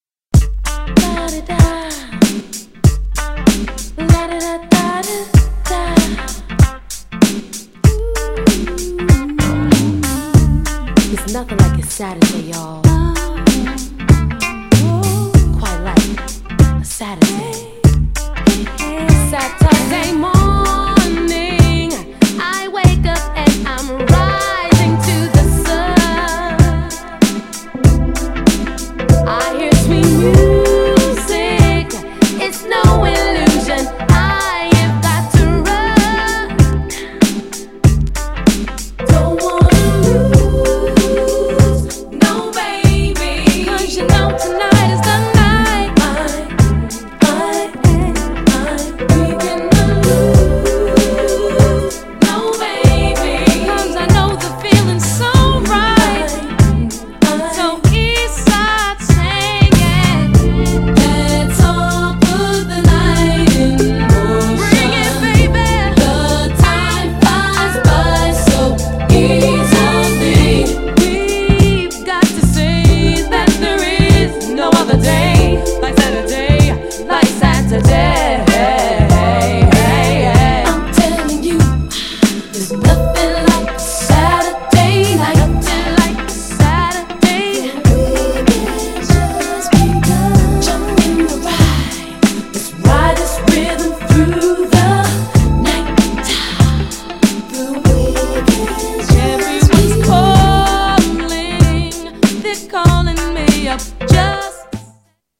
ネオソウル的グルービーなR&Bをメインに、DISC2はメロウなスローを集約したコンセプチュアルで最高なアルバム!!
GENRE R&B
BPM 96〜100BPM
JAZZY
グルーヴ感有 # 女性デュオR&B